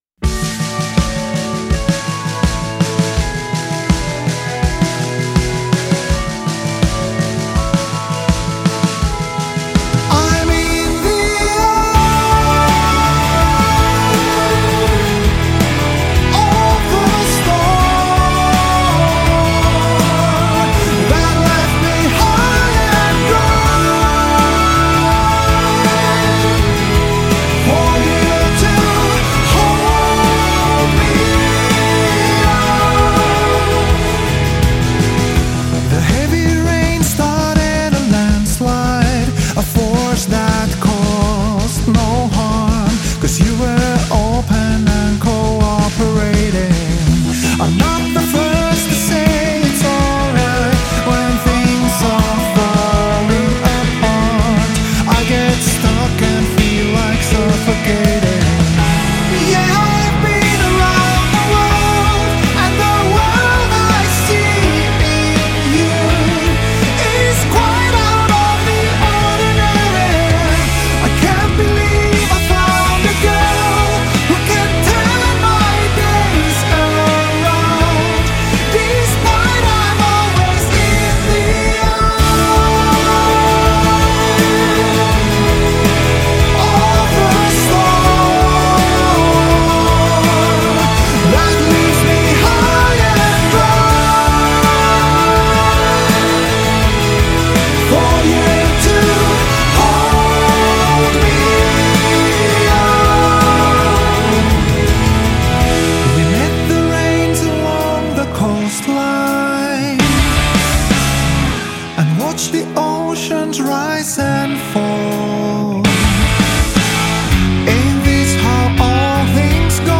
uplifting and anthemic